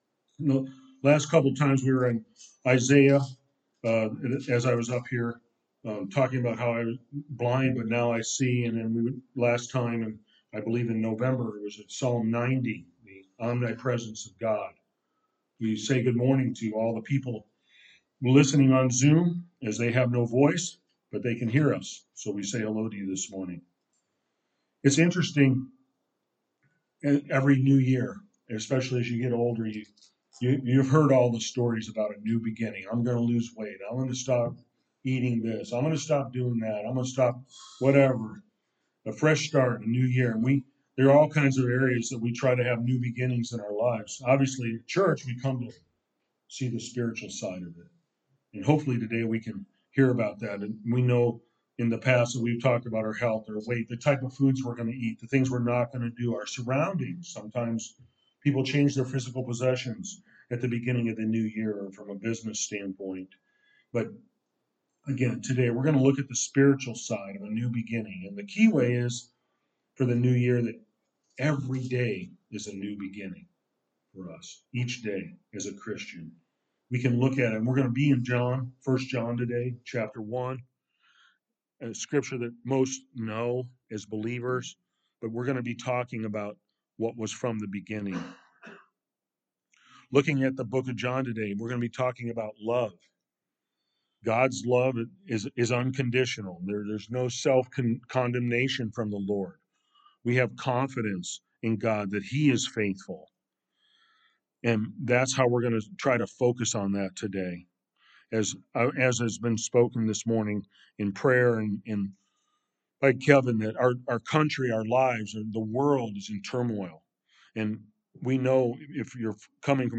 A message from the series "1 John."